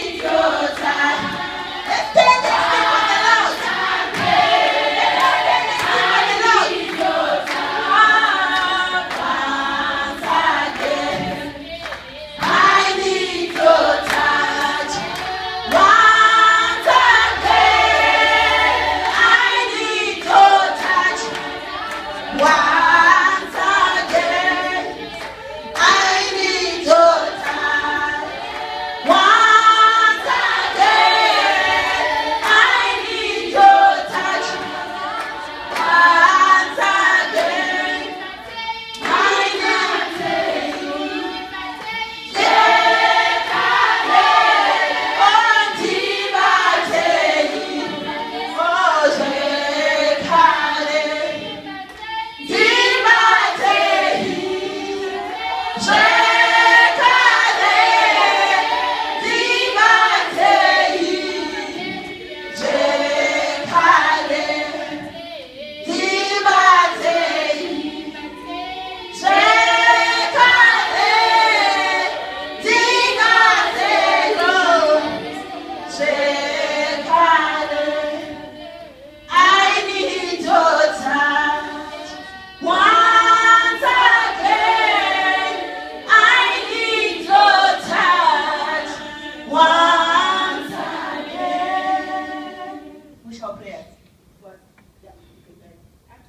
Their songs traveled from somber to soaring, taking good time between points on an ethereal journey.  Their hymns at once carried cries, only to cascade into pools of joy swirled by tempests raging against monsters that might rise before them.  And with energy converging like a storm front, their songs turned into dance, and their dance turned into prayer.